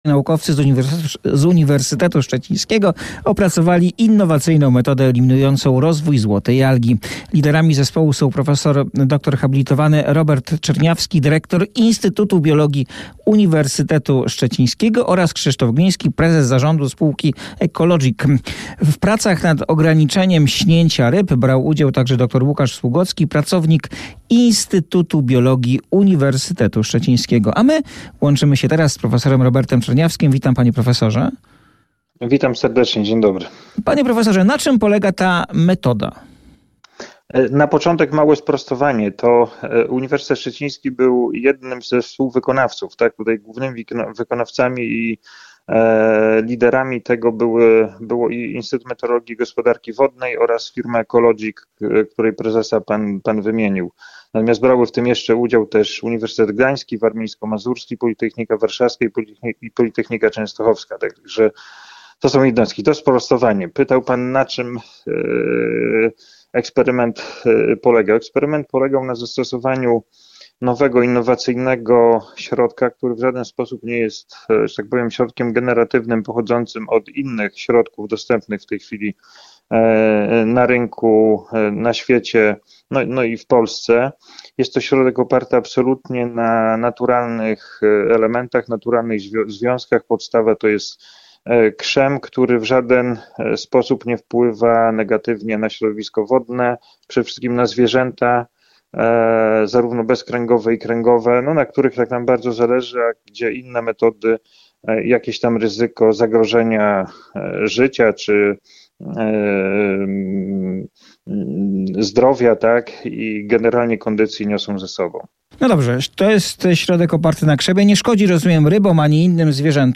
08:00 Fakty i Poranna rozmowa w RMF FM - 21.08.2024